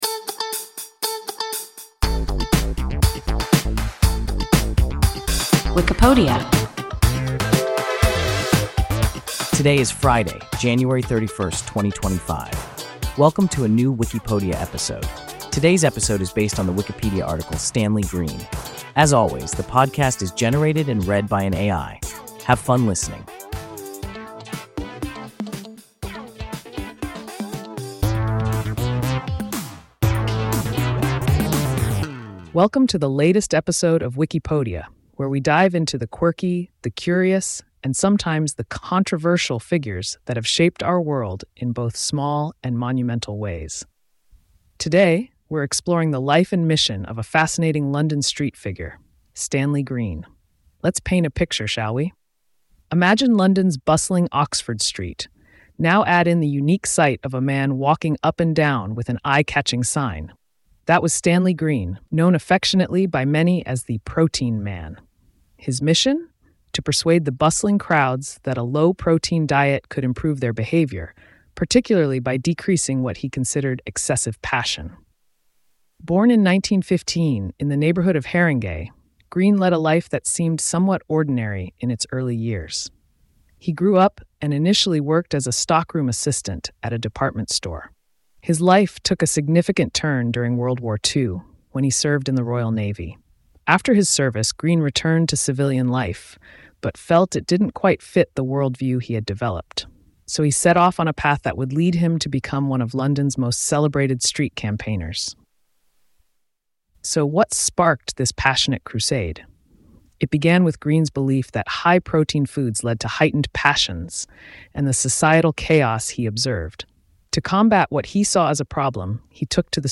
Stanley Green – WIKIPODIA – ein KI Podcast